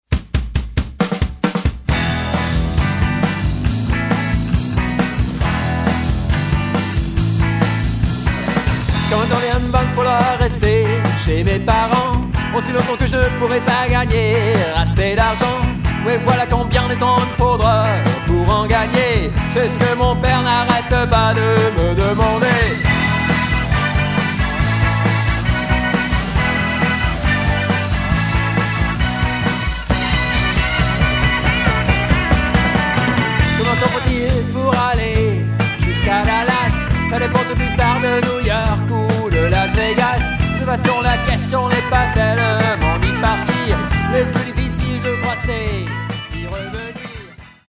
französischen Sixties-Beat, charmante Pop-Songs